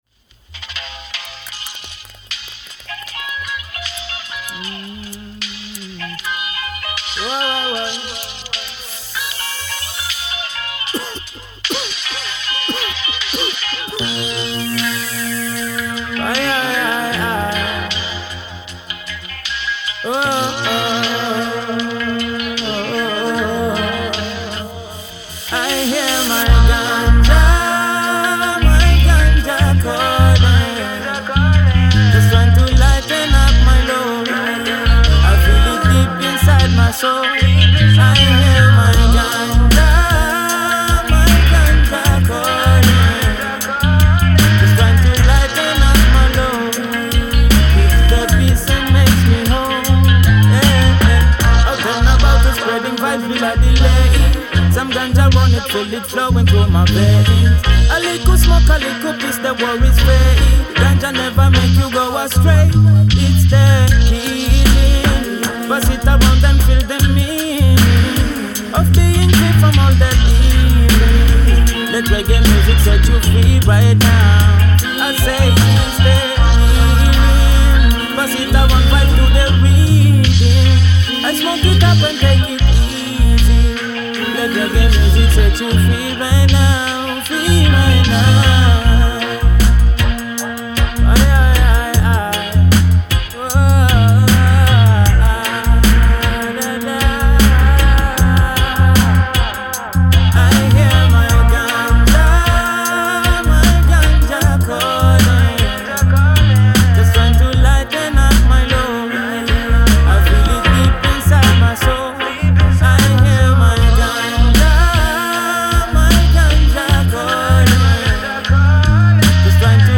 • Style: Reggae